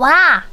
Worms speechbanks
ouch.wav